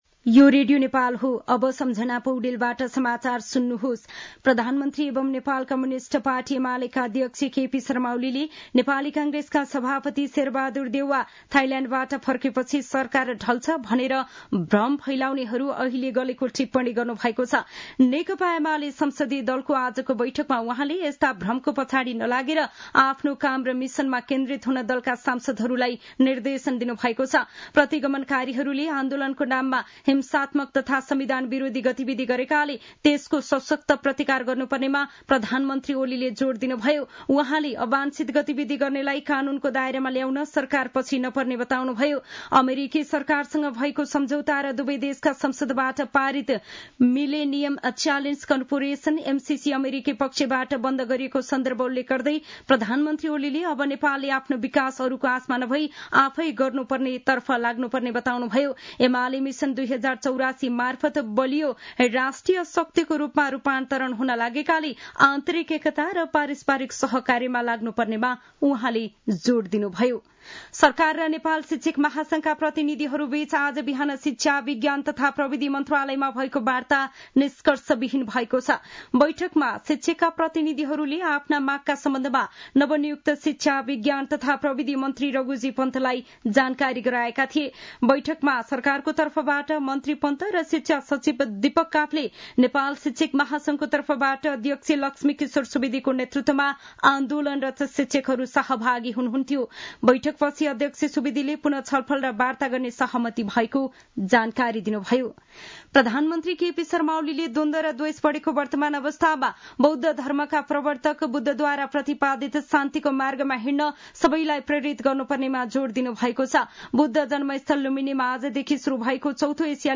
साँझ ५ बजेको नेपाली समाचार : १३ वैशाख , २०८२